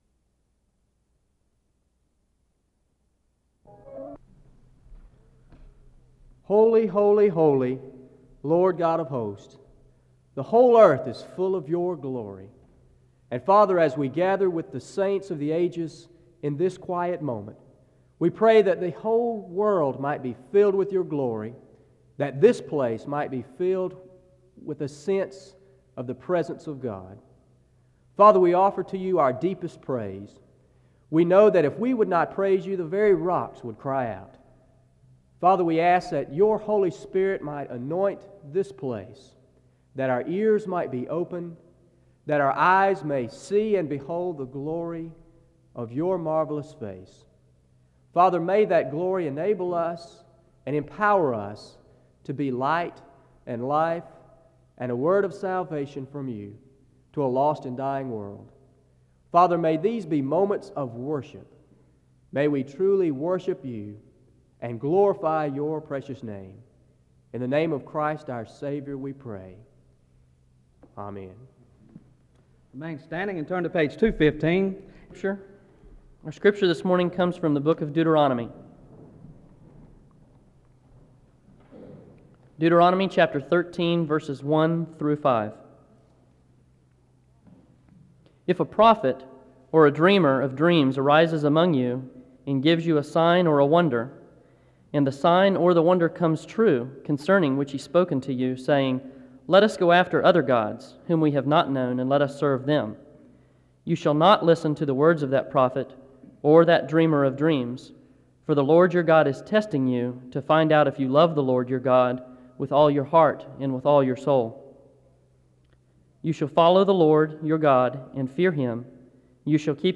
Download .mp3 Description The service starts with a prayer from 0:00-1:11. Deuteronomy 13:1-5 is read from 1:13-2:22. A moment of prayer takes place from 2:27-5:41.
A closing prayer is offered from 14:25-16:53.